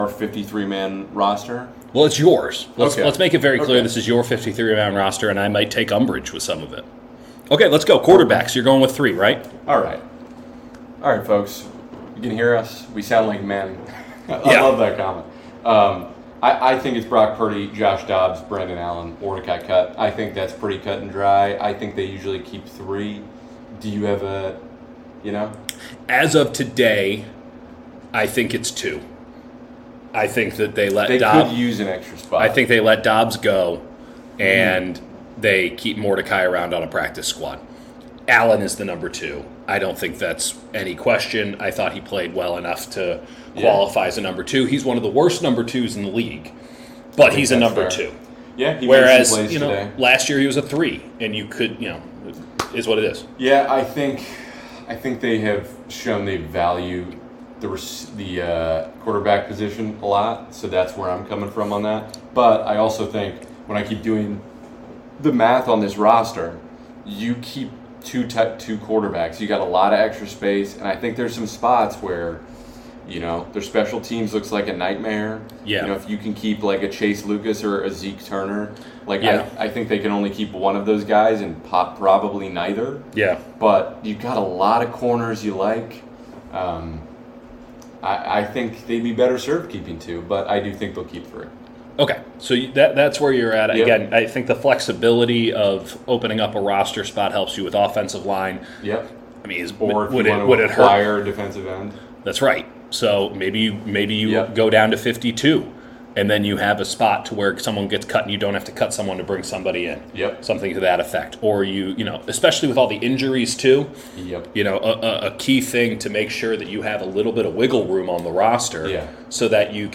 Preseason Opener LIVE | 53-man breakdown, 49ers-Titans reactions